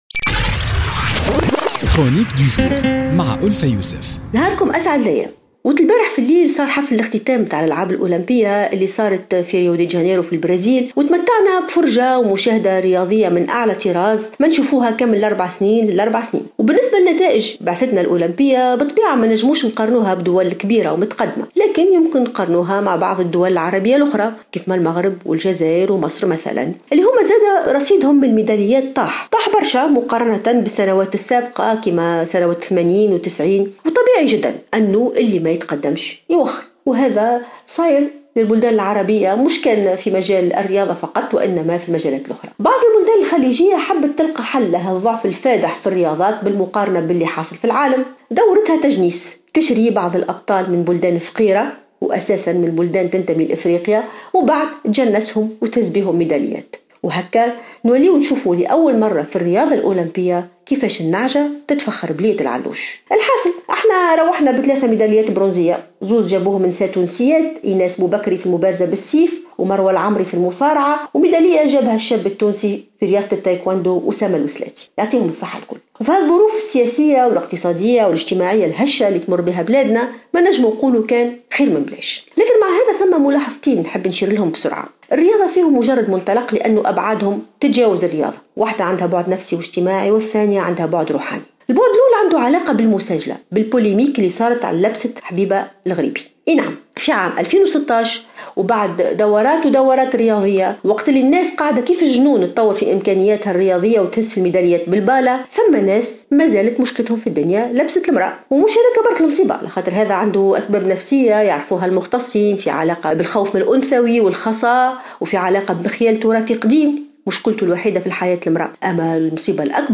تطرقت الكاتبة ألفة يوسف في افتتاحية اليوم الثلاثاء إلى المشاركة التونسية في أولمبياد 2016 بريو دي جنيرو بالبرازيل ونصيب تونس من الميداليات .